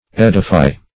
Edify \Ed"i*fy\, v. i.